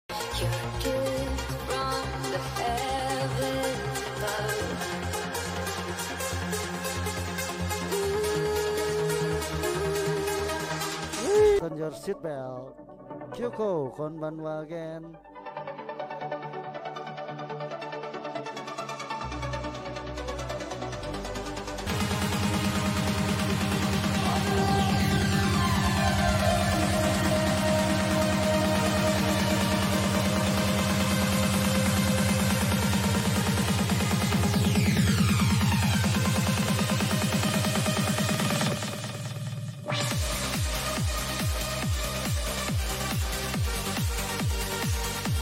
138 BPM